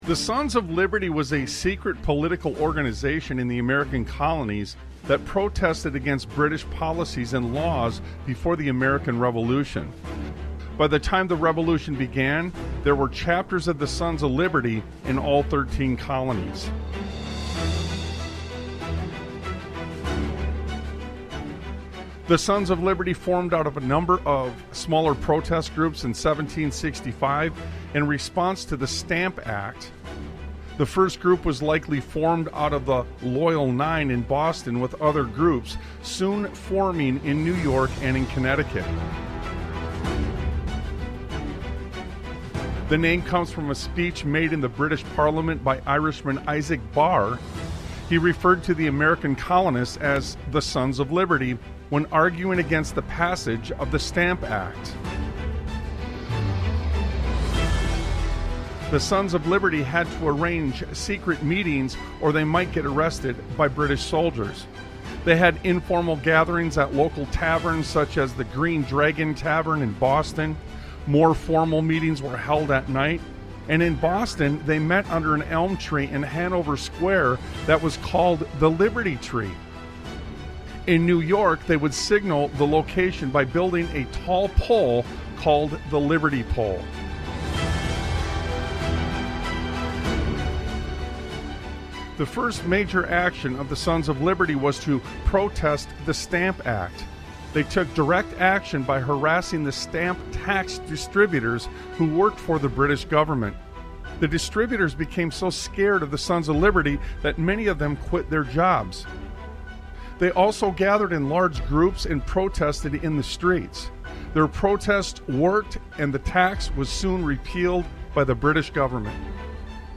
Talk Show Episode, Audio Podcast, Sons of Liberty Radio and Lines Have Been Crossed Long Ago on , show guests , about Lines Have Been Crossed Long Ago, categorized as Education,History,Military,News,Politics & Government,Religion,Christianity,Society and Culture,Theory & Conspiracy